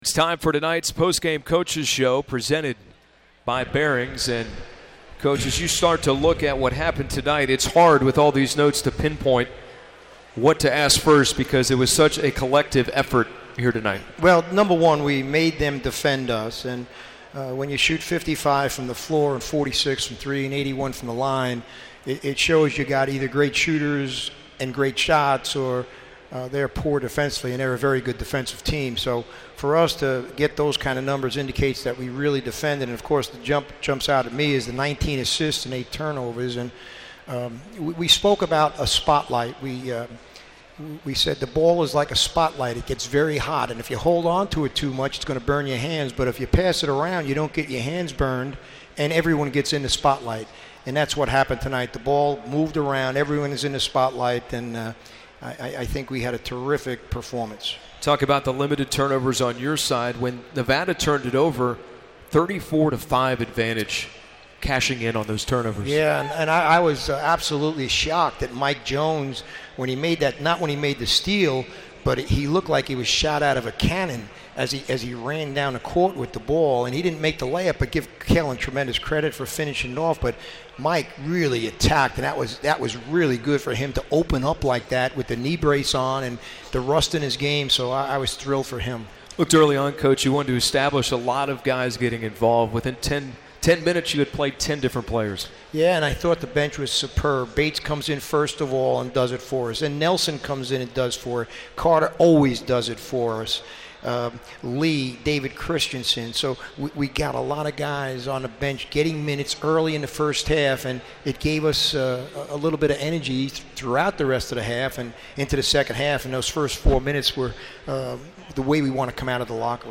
McKillop Radio Interview
McKillop Postgame Nevada.mp3